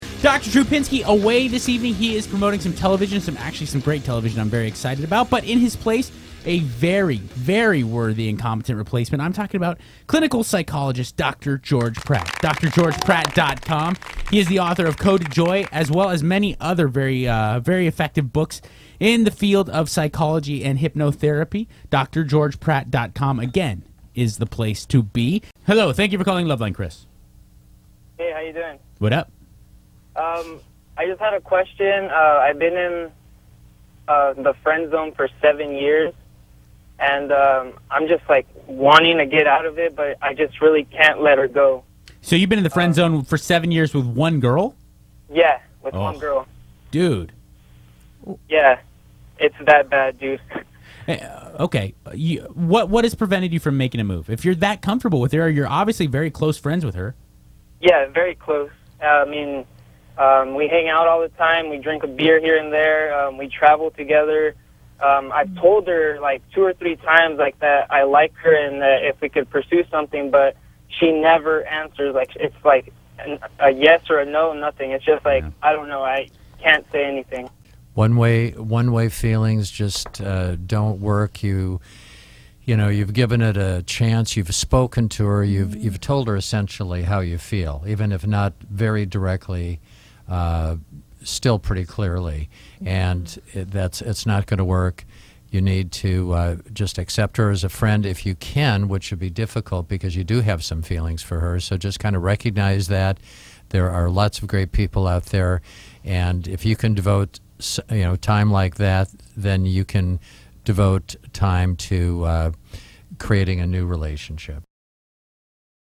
Loveline is a call-in question-and-answer radio show with the primary goal of helping youth and young adults with relationship, sexuality, and drug addiction problems.